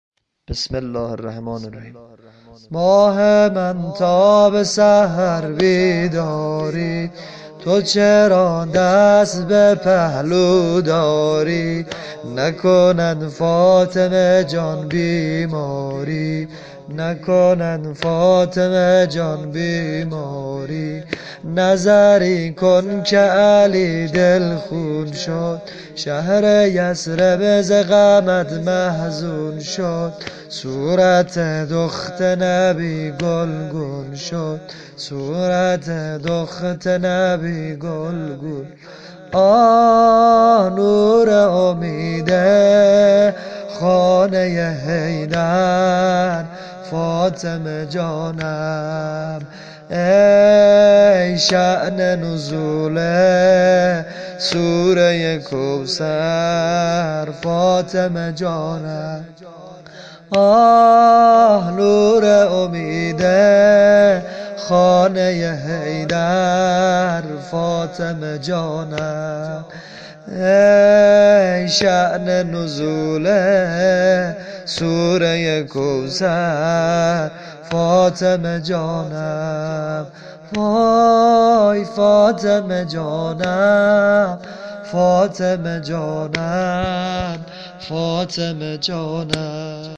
سبک واحد فاطمیه -(ماه من تا به سحر بیداری)